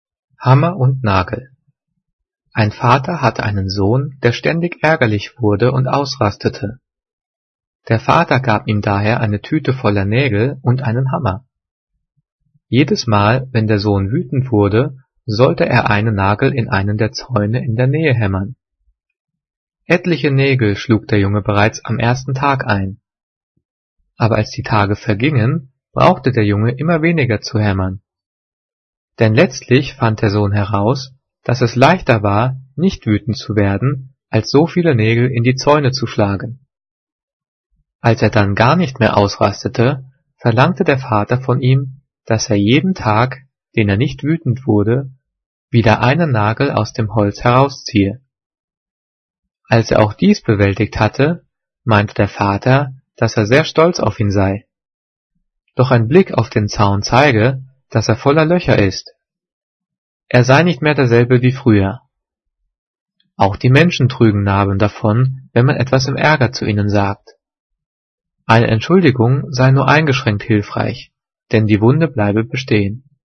Übrigens, die Satzzeichen werden außer beim Thema "Zeichensetzung" und den Übungsdiktaten der 9./10. Klasse mitdiktiert.
Gelesen:
gelesen-hammer-und-nagel.mp3